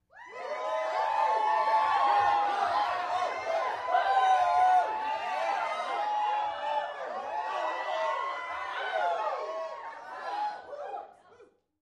( 1-2 ) Crowd ( 20 ): Rowdy, Short Cheers, Mostly Male. Cheers, Male.